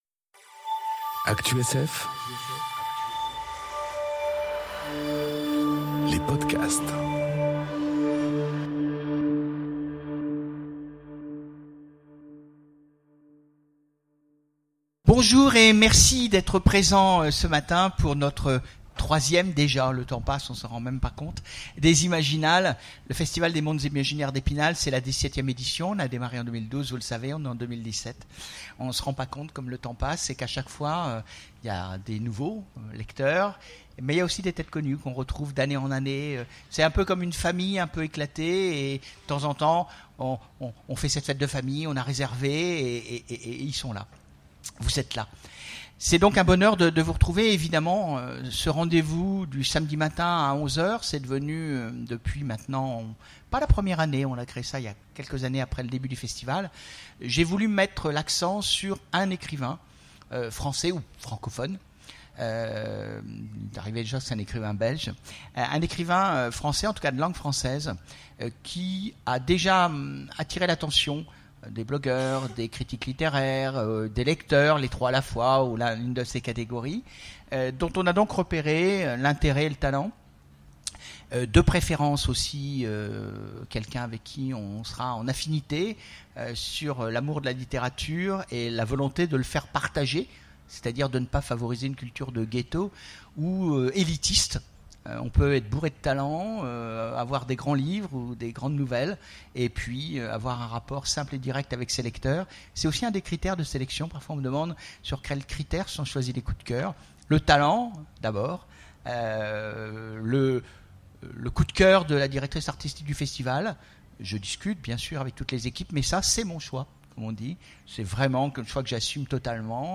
enregistrée aux Imaginales 2018
Conférence
Rencontre avec un auteur